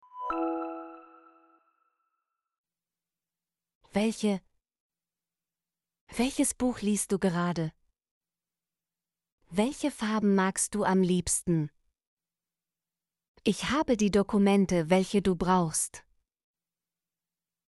welche - Example Sentences & Pronunciation, German Frequency List